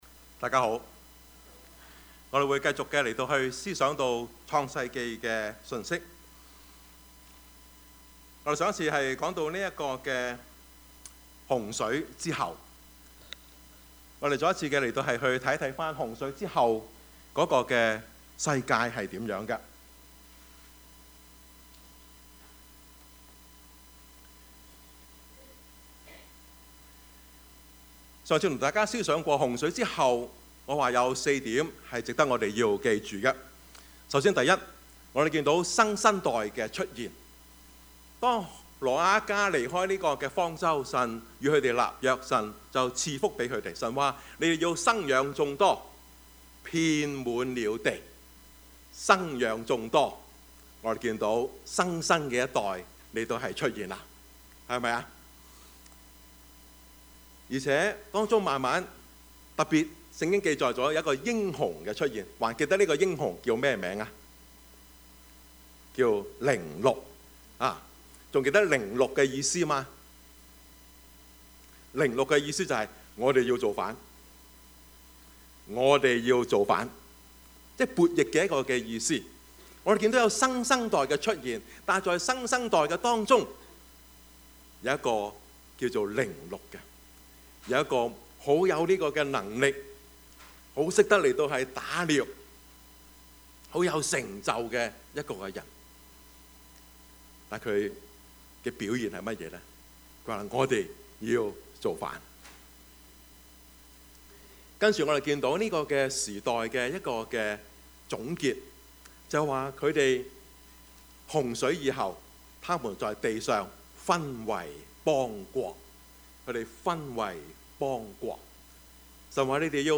Service Type: 主日崇拜
Topics: 主日證道 « 異象, 使命, 行動 神的揀選 »